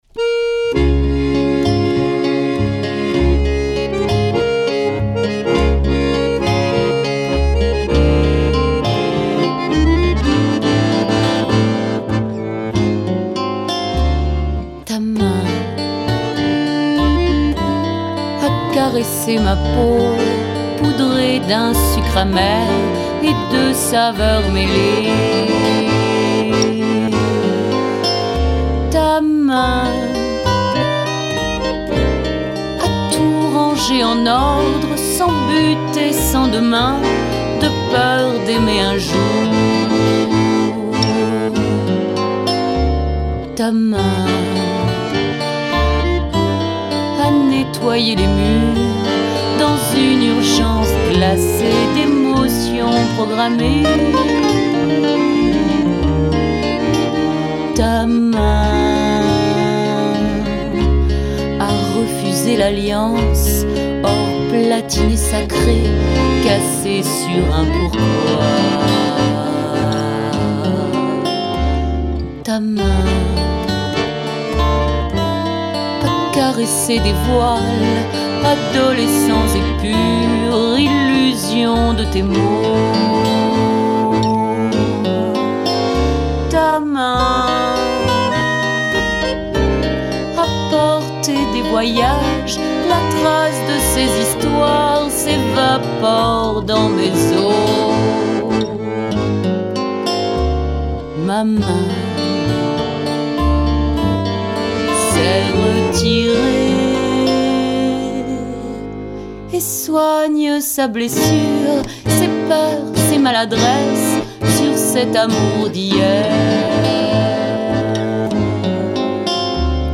LES CHANSONS TANGOS